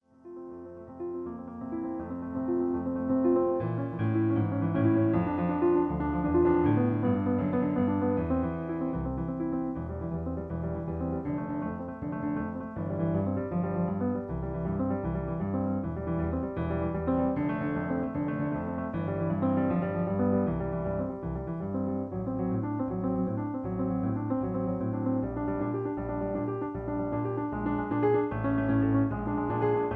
In F. Piano Accompaniment